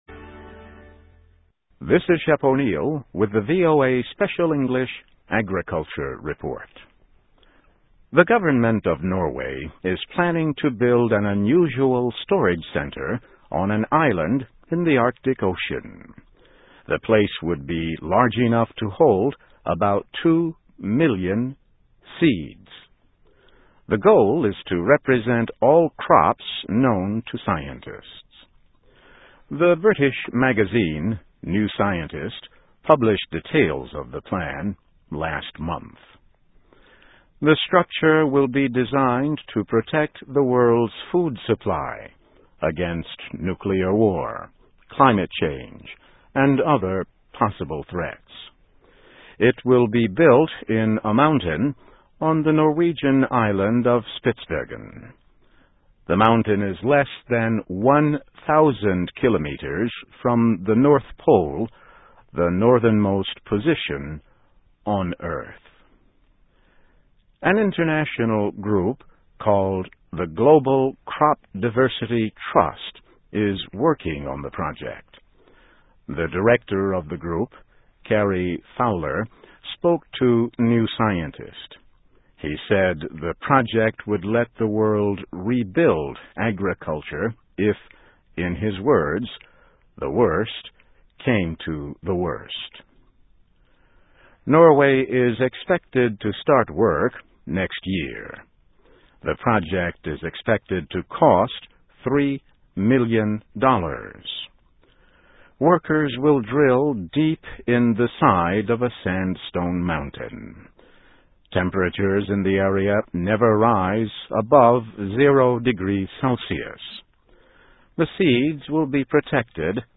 Norway Plans to Store Seeds of All the World's Crops (VOA Special English 2006-02-07)